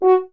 016_FH F#4 SCF.wav